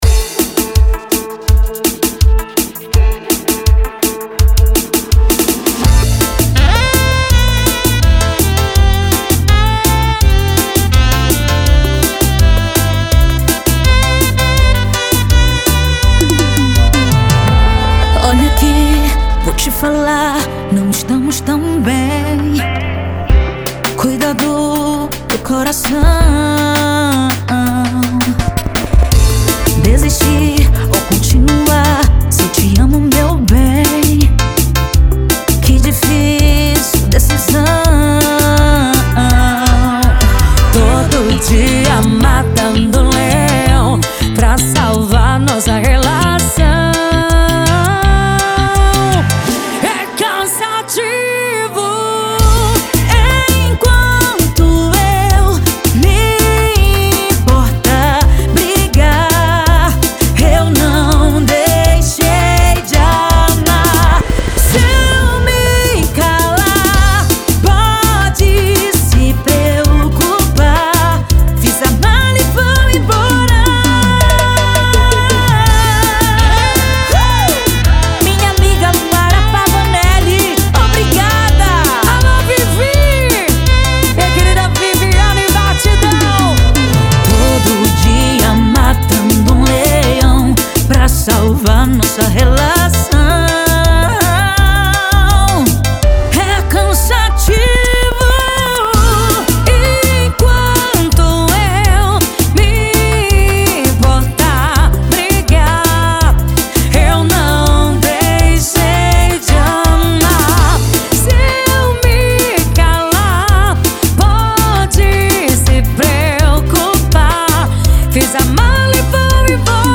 Melody